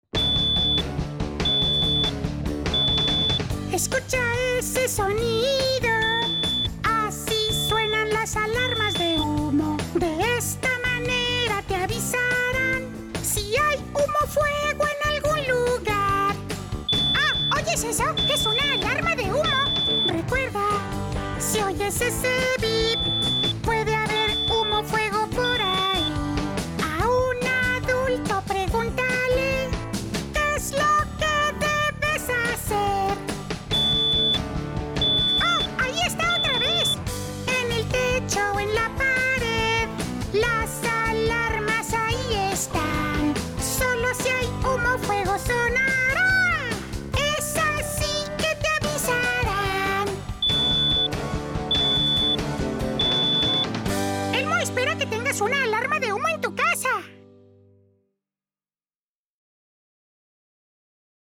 Songs and stories